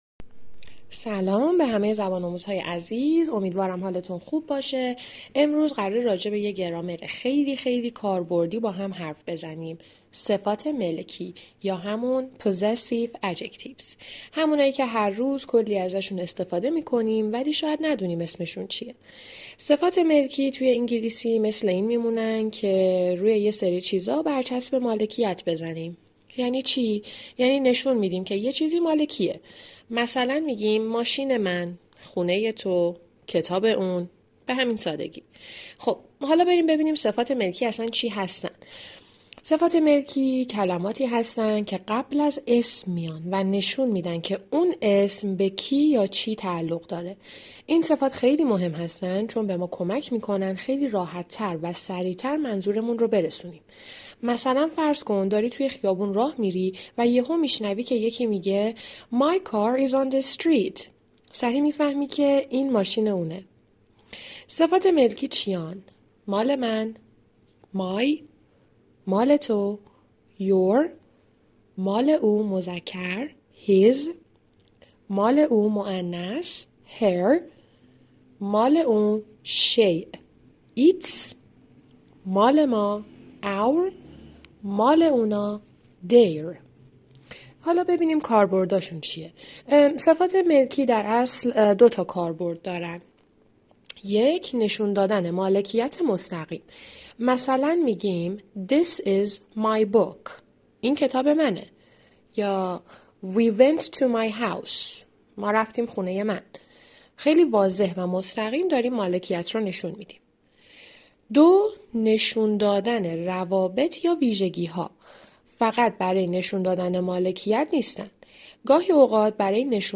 آموزش صفات ملکی به زبان ساده با مثال و نمونه (آموزش صوتی)
این آموزش توسط مدرسان آموزشگاه زبان سفیر گفتمان ارائه شده است؛ لطفا پلی کنید!